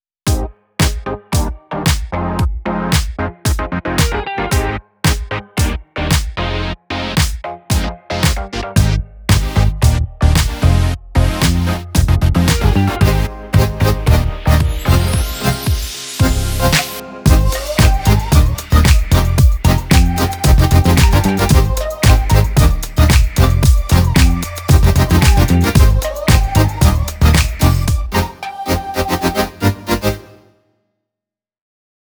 PARTY SYNTH POP
Club / Relaxed / Positive / Funky